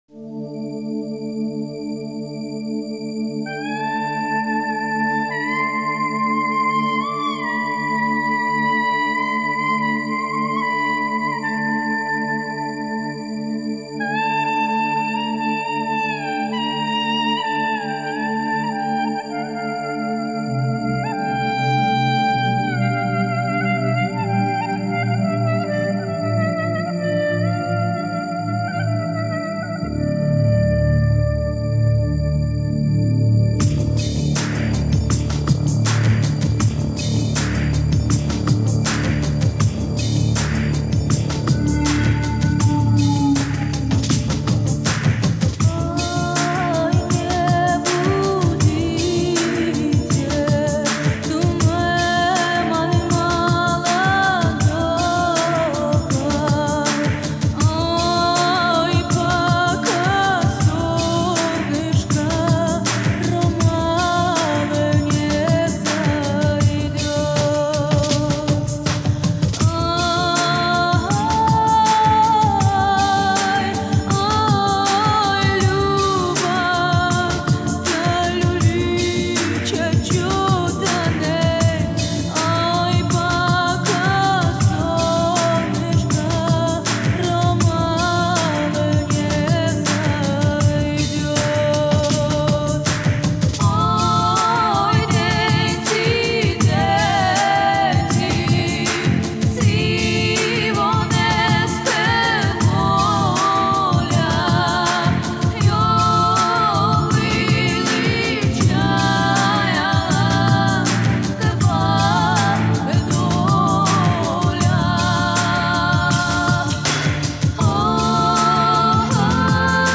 цыганский романс